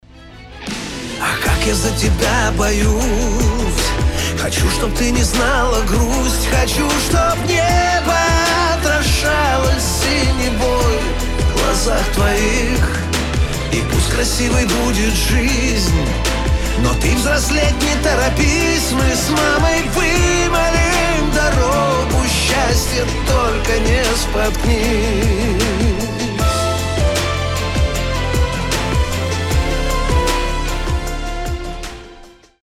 Рингтоны шансон
Душевные